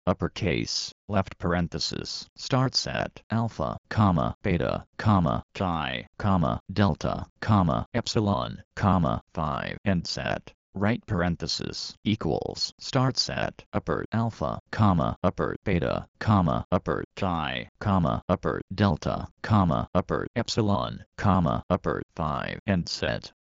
Greek letters are spoken by there Greek names. How exactly they are pronounced will depend on the speech engine.
verbose